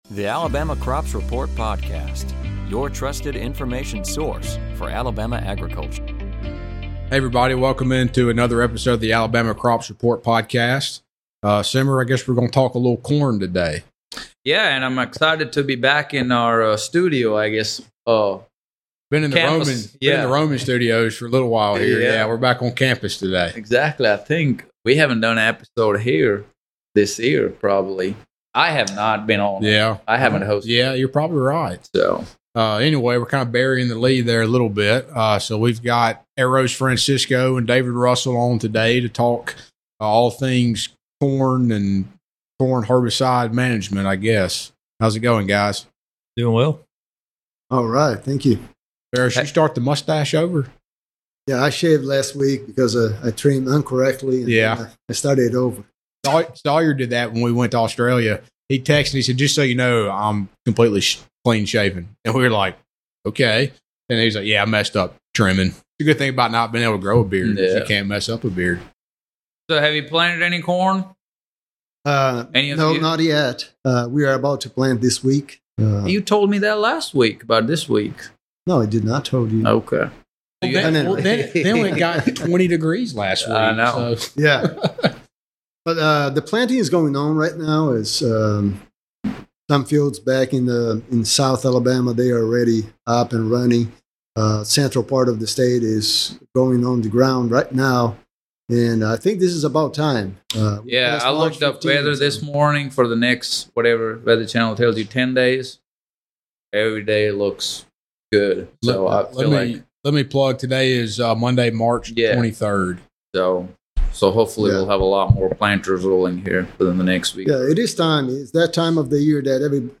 Corn planting is underway in Alabama, and farmers are cautiously optimistic about the season. The crew discusses the concerns of continued increases of input costs as well as low commodity prices due to high domestic supply and limited exports. They also talk about lessons from recent growing seasons, weed management, cover crops, planter setup and seeding rate recommendations.